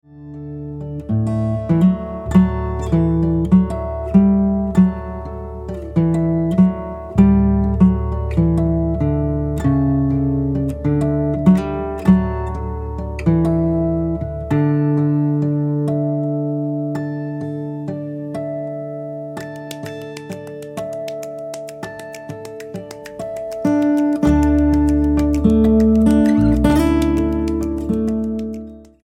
STYLE: Pop
instrumental renditions of carols
a pleasant and relaxing collection